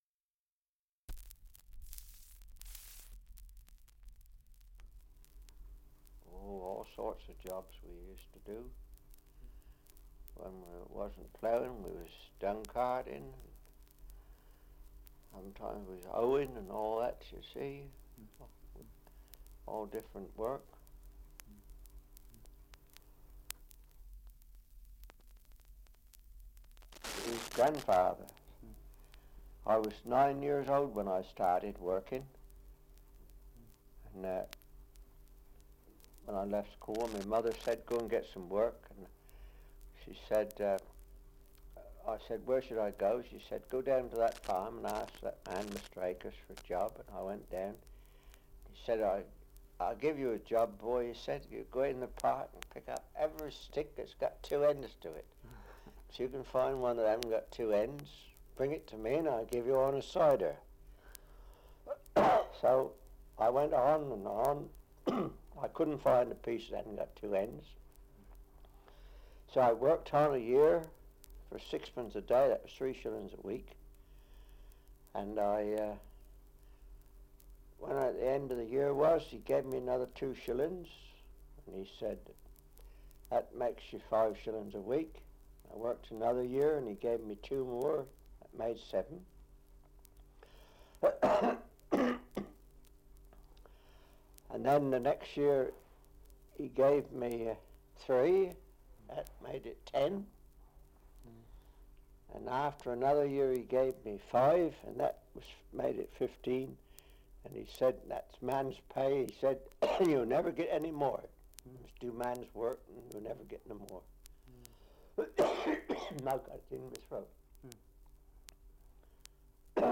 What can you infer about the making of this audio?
Survey of English Dialects recording in East Clandon, Surrey 78 r.p.m., cellulose nitrate on aluminium